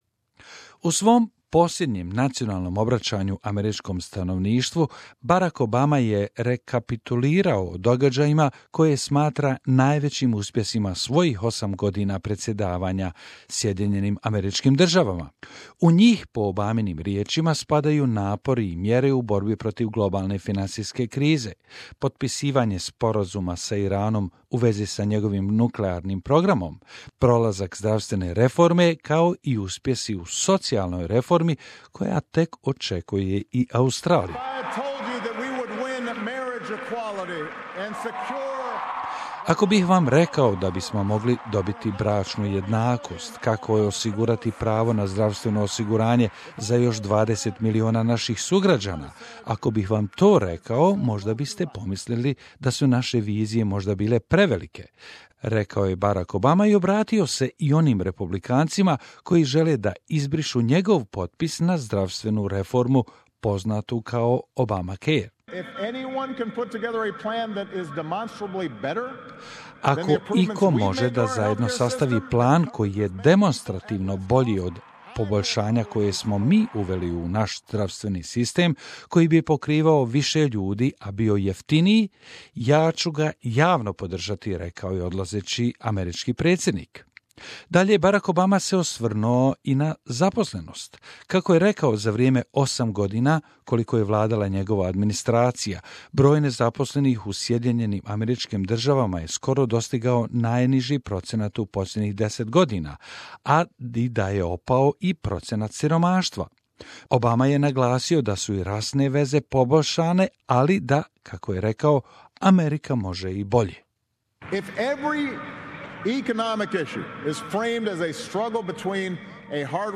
Obama delivers farewell address
US President Barack Obama has delivered his farewell address, urging Americans to defend their democracy. During the address in Chicago, Mr Obama warned democracy is threatened whenever people take it for granted. He mentioned three main threats to America's democracy - economic inequality, racial divisions and the tendency for people to retreat into their own bubbles.